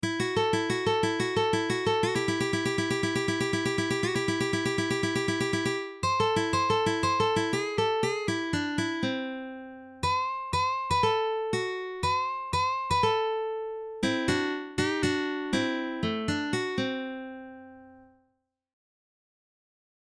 Exercice > Licks For Lead Guitar
Licks+For+Lead+Guitar.mp3